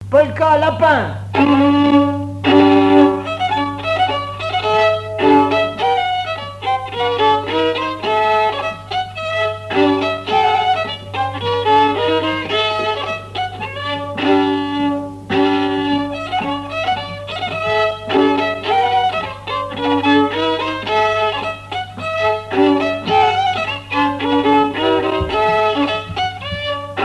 danse : polka lapin
Pièce musicale inédite